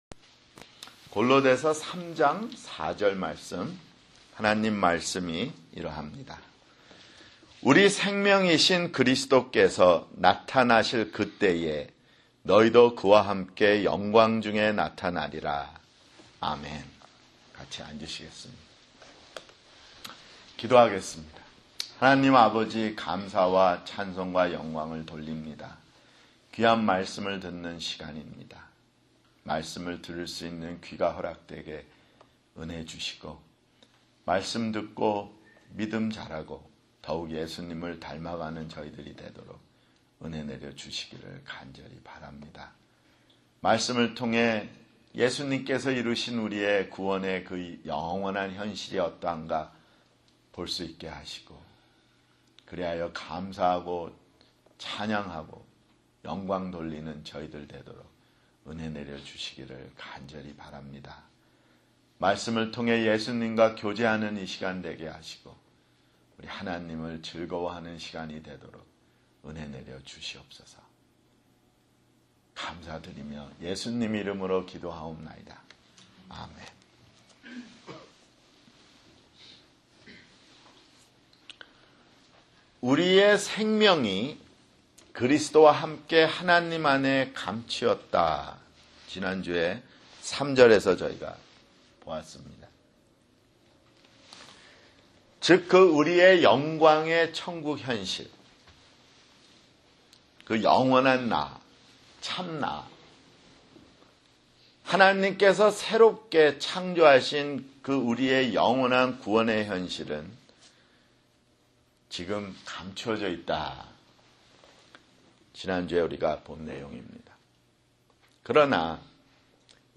[주일설교] 골로새서 (57)